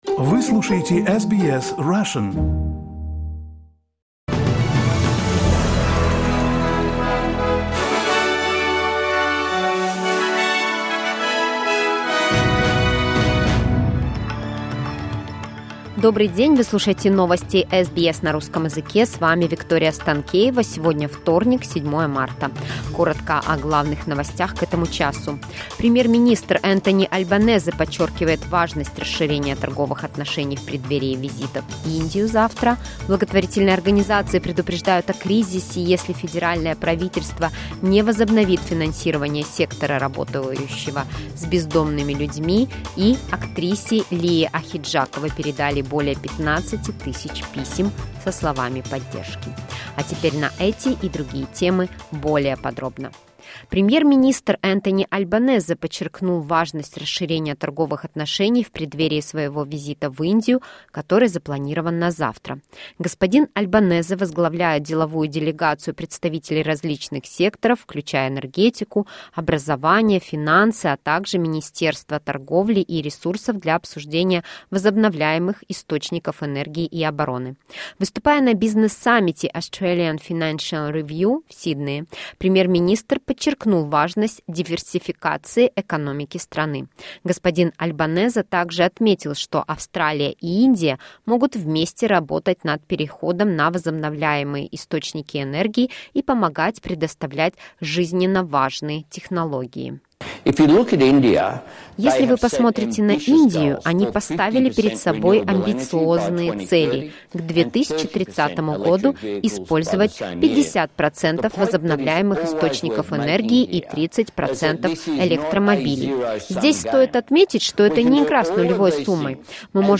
SBS news in Russian — 07.03.2023